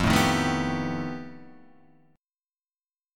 F9b5 chord